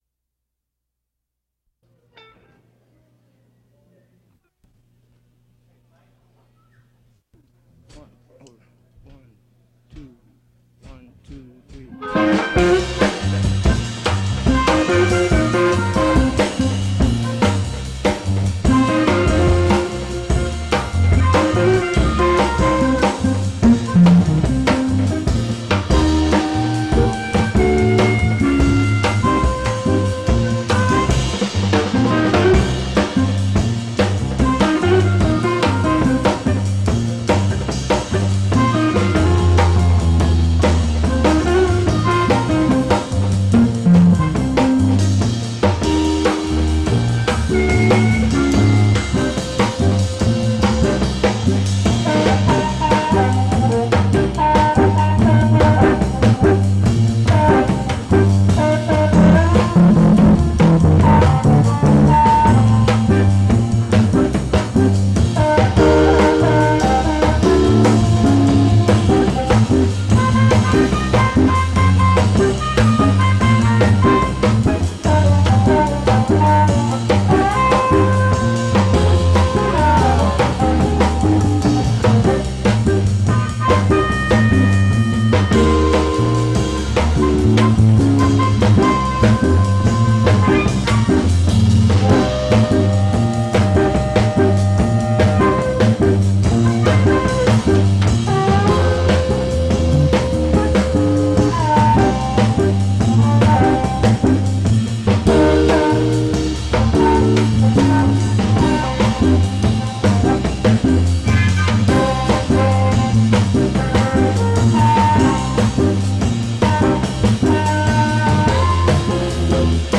A live recording